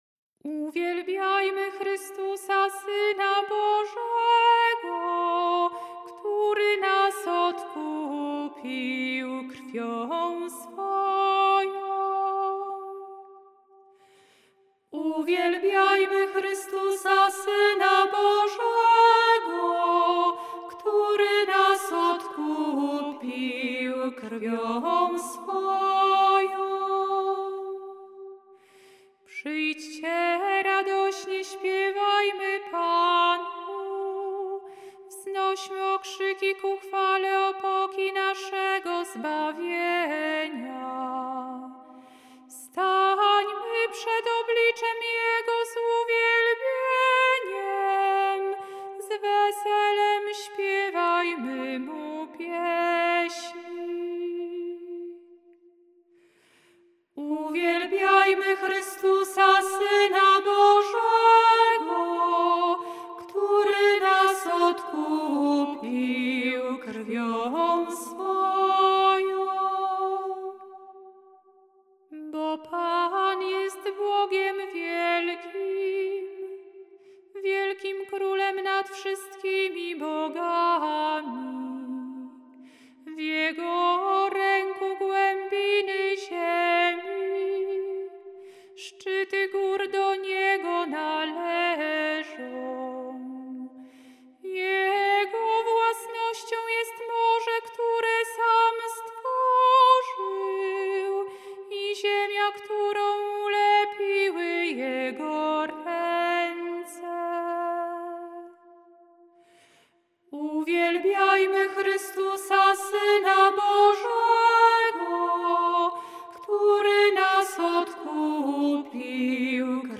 Dlatego do psalmów zastosowano tradycyjne melodie tonów gregoriańskich z ich różnymi, często mniej znanymi formułami kadencyjnymi (tzw. dyferencjami).
Dla pragnących przygotować się do animacji i godnego przeżycia tych wydarzeń liturgicznych przedstawiamy muzyczne opracowanie poszczególnych części wykonane przez nasze siostry